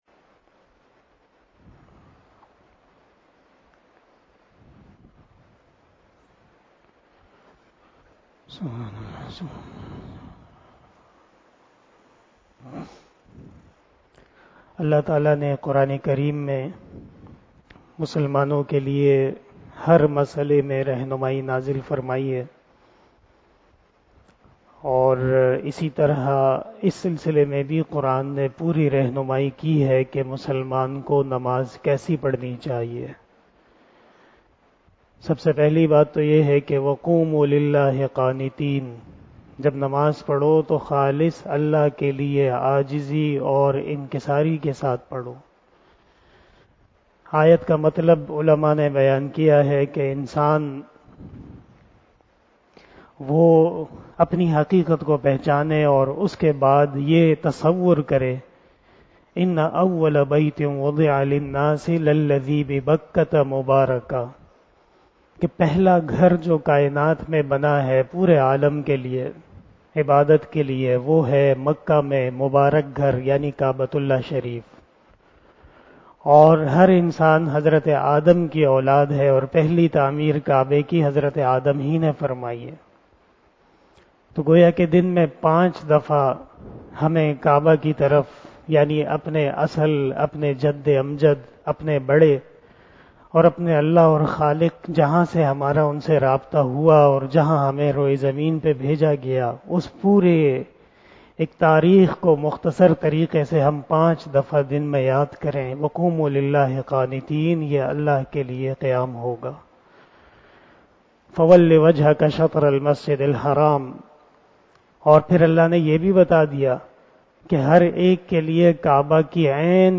046 After Asar Namaz Bayan 18 April 2022 ( 17 Ramadan 1443HJ) Monday